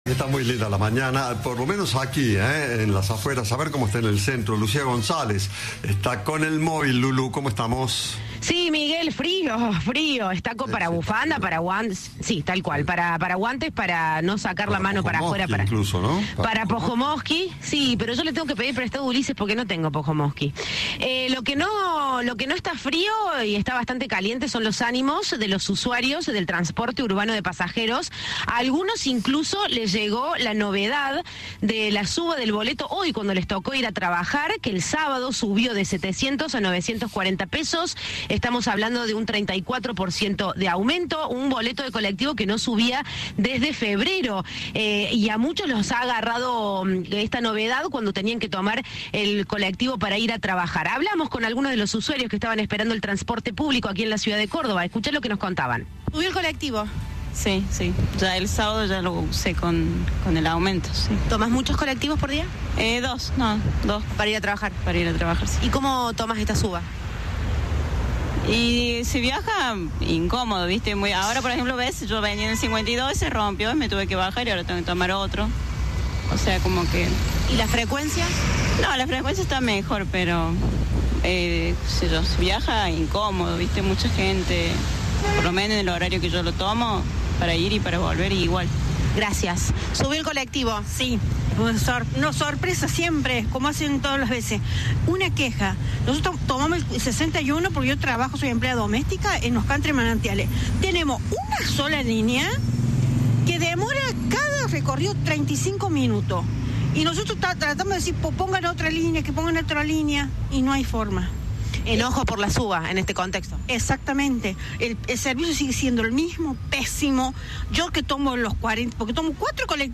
La opinión general en las paradas de colectivo es de enojo y sorpresa, ya que muchos usuarios se están enterando del aumento en el momento de abordar el transporte.